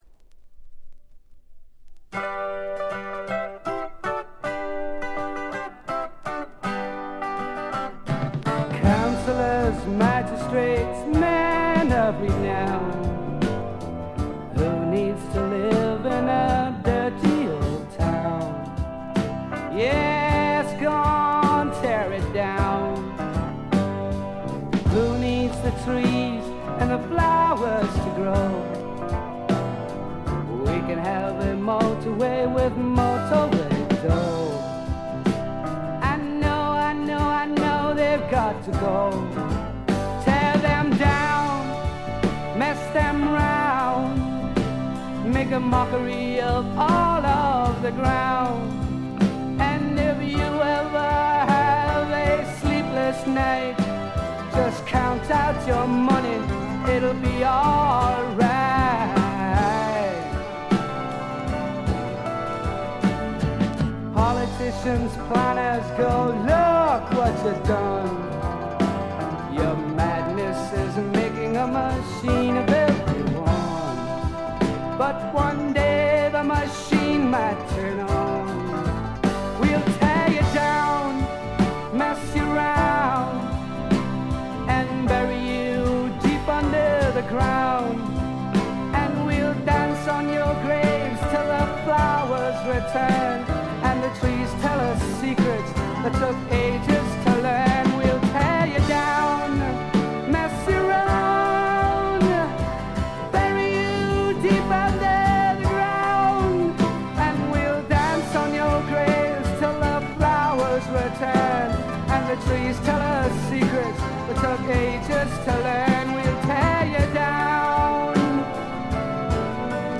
ほとんどノイズ感無し。
これぞ英国流フォークロックとも言うべき名作です。
試聴曲は現品からの取り込み音源です。
※A1-A3メドレー連続です